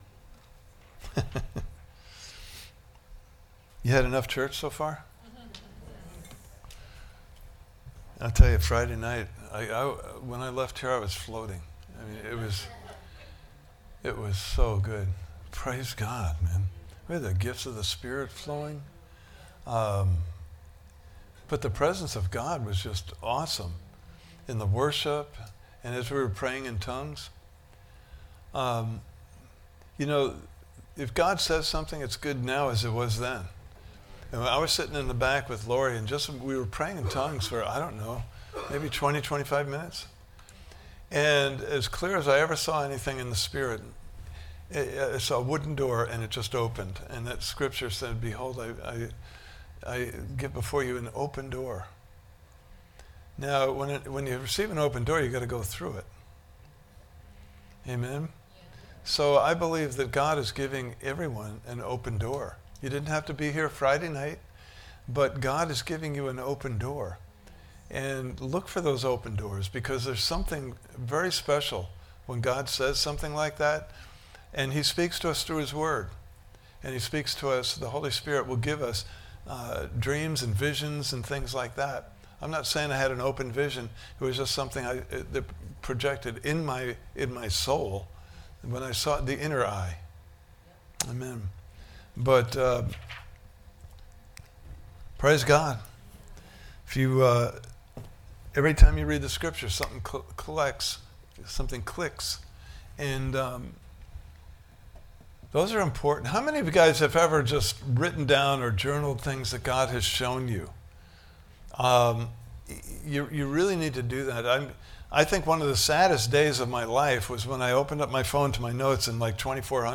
Series: The Wonderful Joy of the Lord! Service Type: Sunday Morning Service « Part 5: Grateful for Kingdom Harvests!